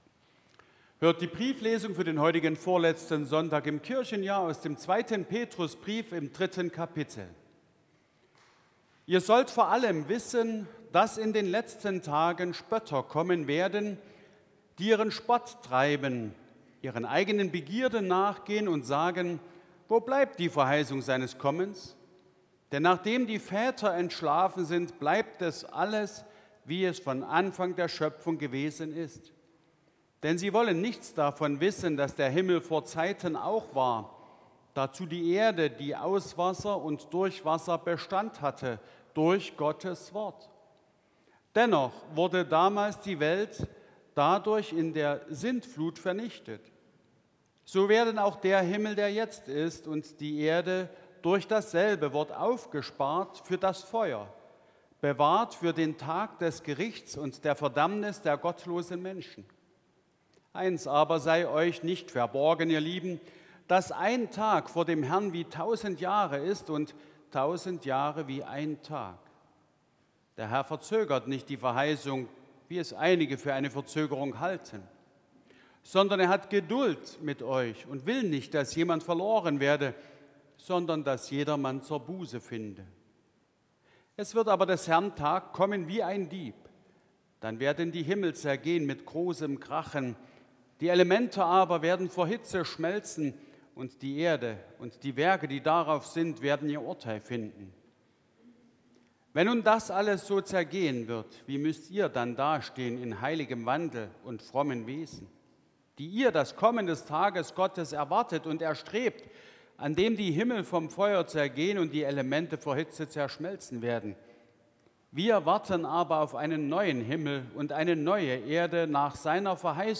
Audiomitschnitt unseres Gottesdienstes vom Vorletzten Sonntag im Kirchenjahr 2024